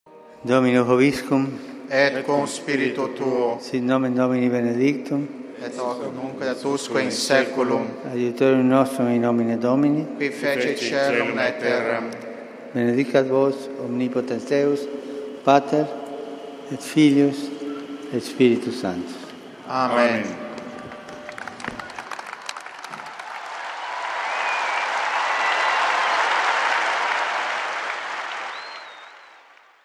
The Pope’s general audience concluded with his blessing.